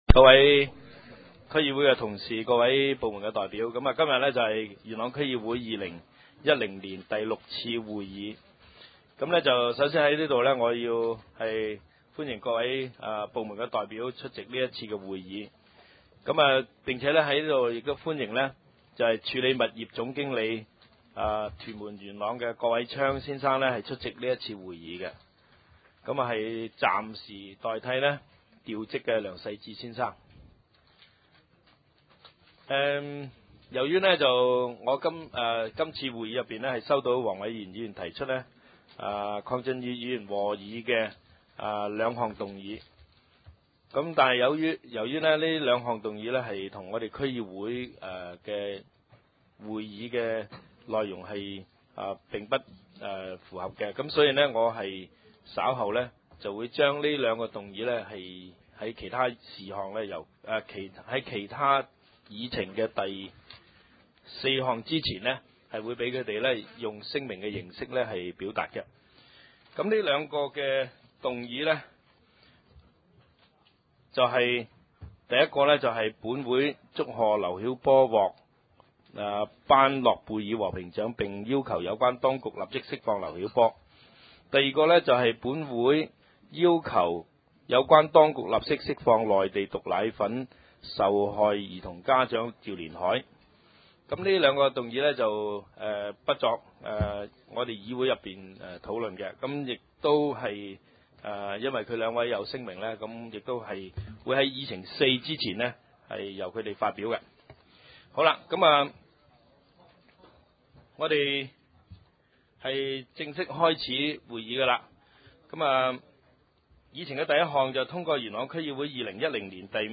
點：元朗橋樂坊二號元朗政府合署十三樓元朗區議會會議廳